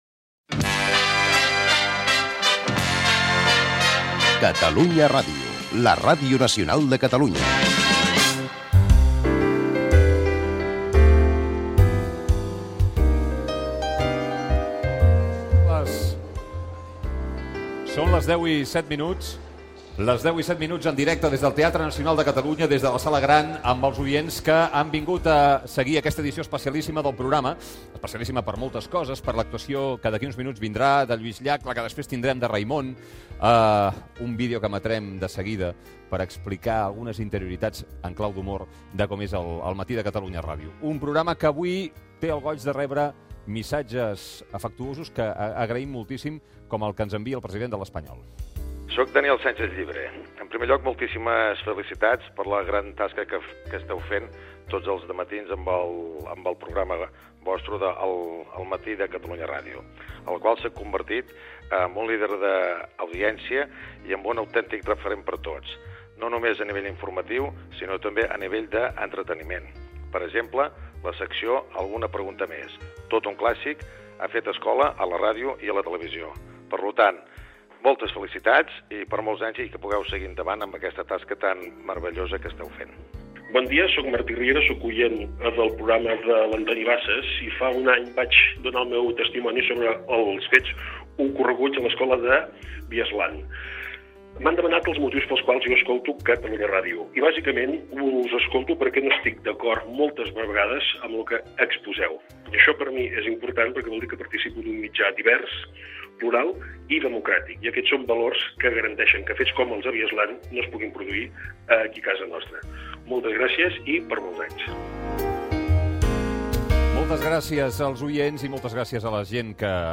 "El criptograma" i publicitat.